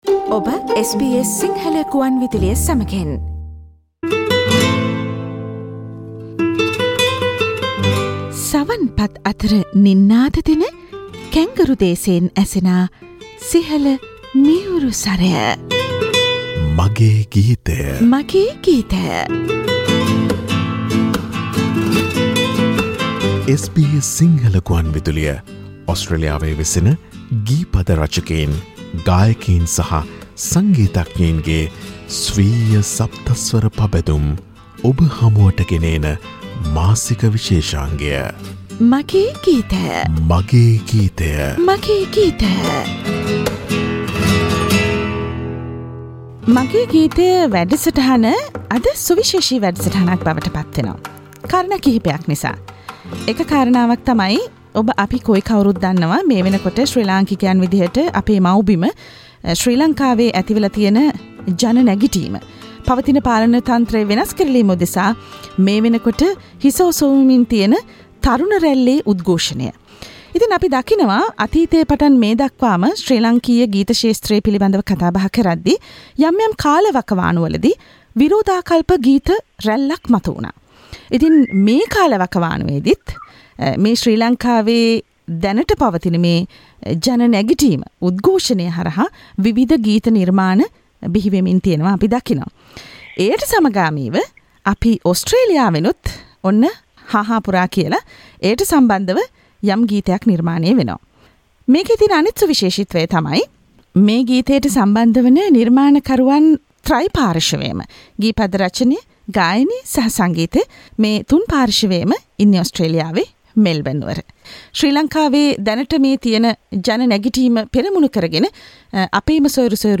Mucical program